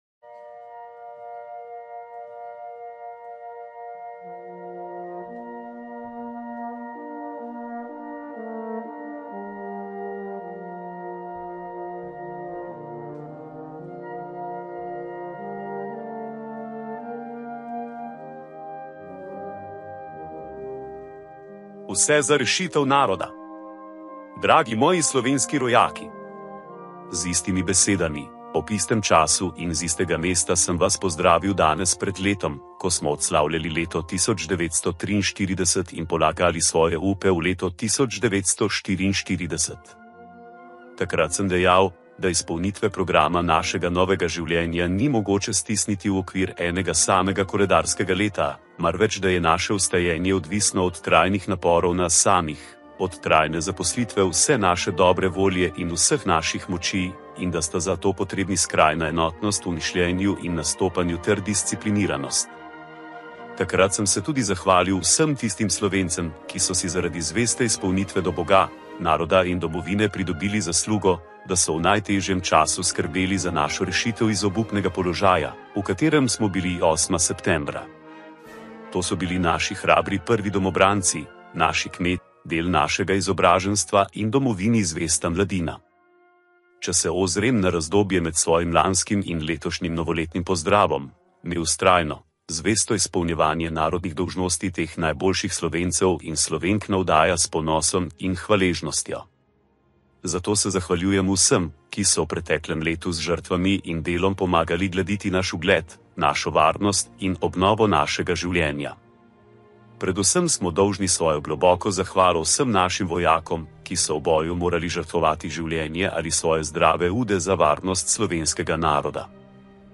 Leon Rupnik's 1945 New Year's radio speech - Everything for the Salvation of the Nation!